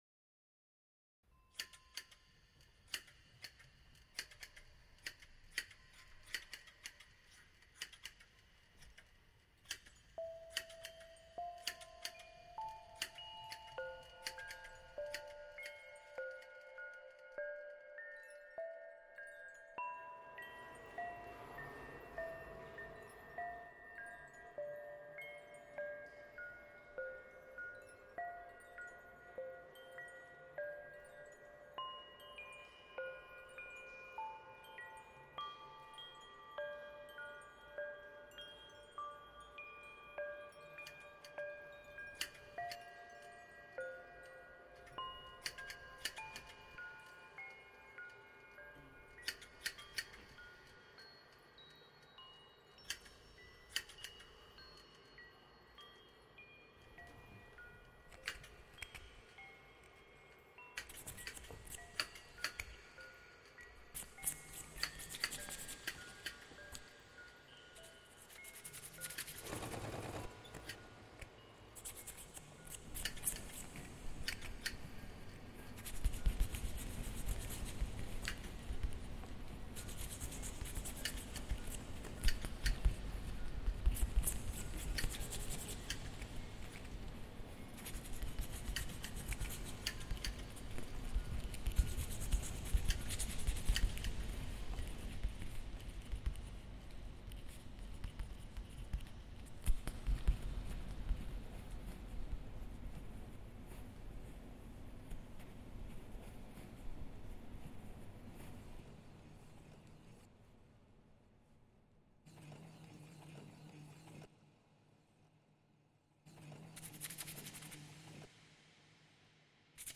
Greek goat shearing reimagined